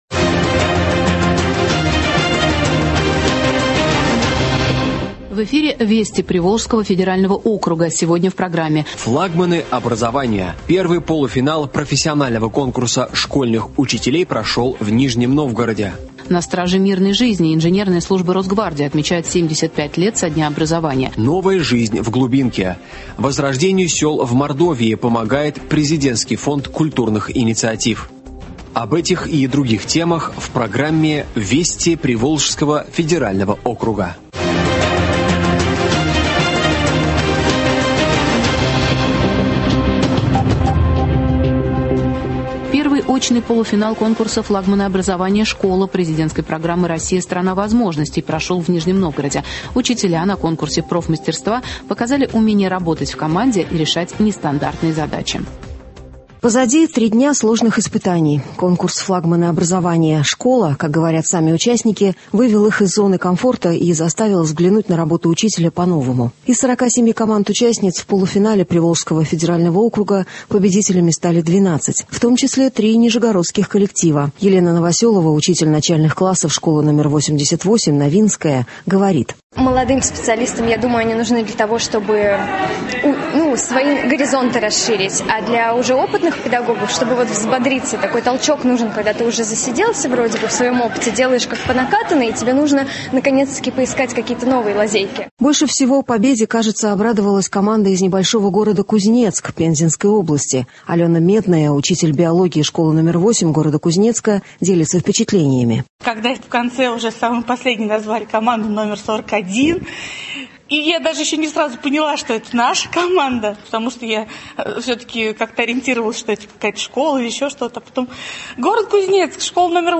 Радиообзор событий в регионах ПФО .